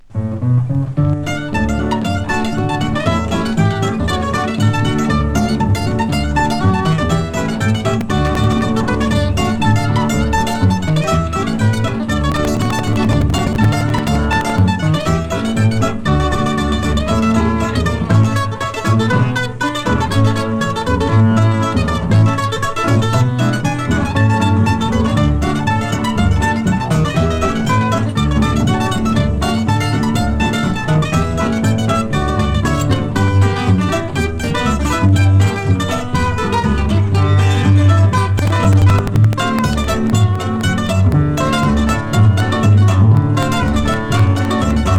Jazz　USA　12inchレコード　33rpm　Stereo